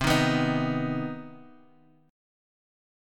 Listen to CmM7 strummed